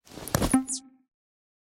pda_holster.ogg